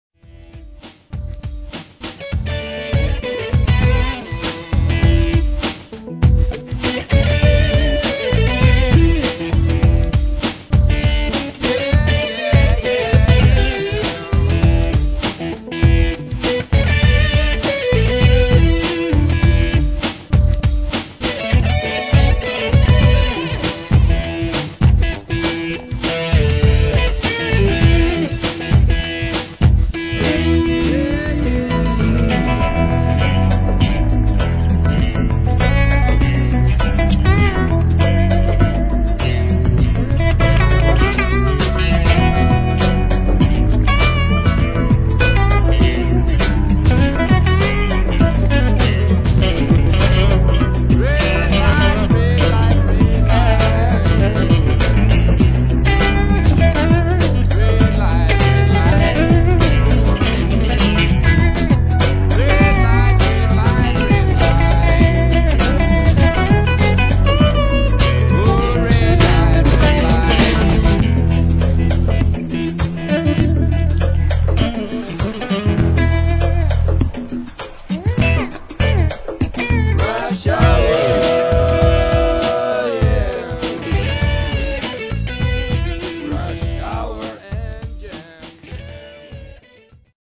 električne gitare